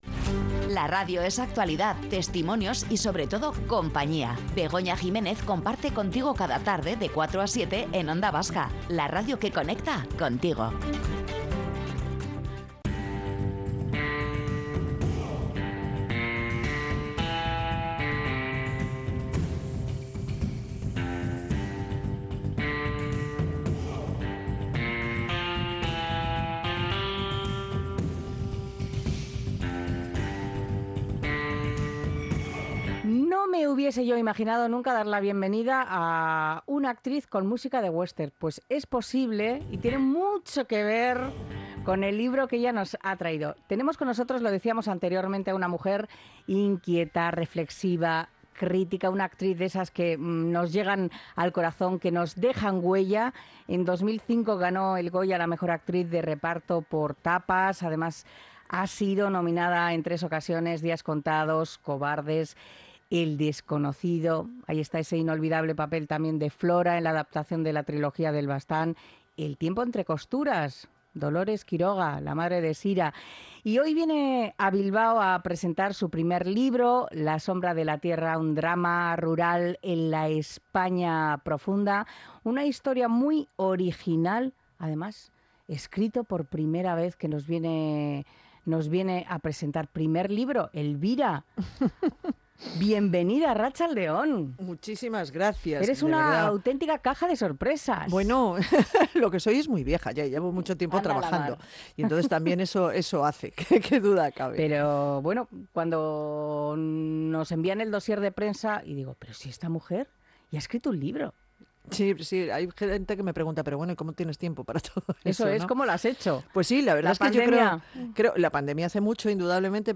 La actriz Elvira Minguez presenta su primera novela "La sombra de la tierra" - Onda Vasca